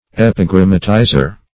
Search Result for " epigrammatizer" : The Collaborative International Dictionary of English v.0.48: Epigrammatizer \Ep`i*gram"ma*ti`zer\, n. One who writes in an affectedly pointed style.